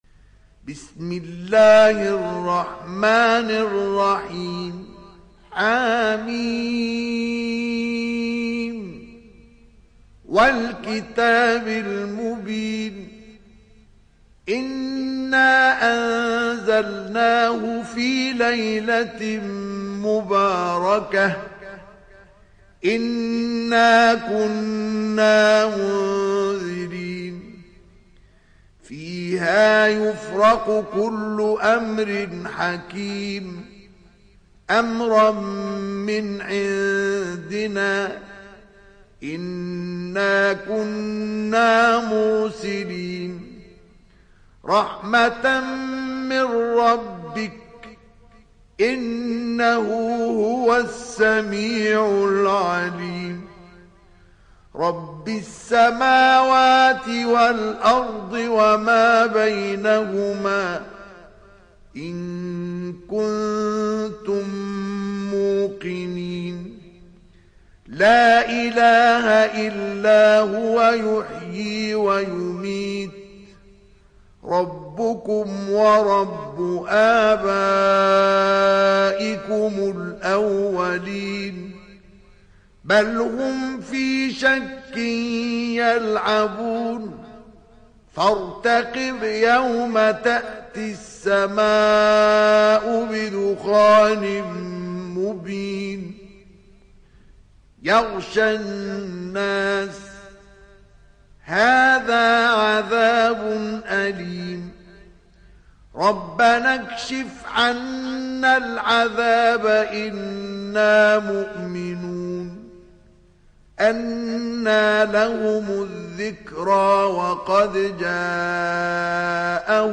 تحميل سورة الدخان mp3 بصوت مصطفى إسماعيل برواية حفص عن عاصم, تحميل استماع القرآن الكريم على الجوال mp3 كاملا بروابط مباشرة وسريعة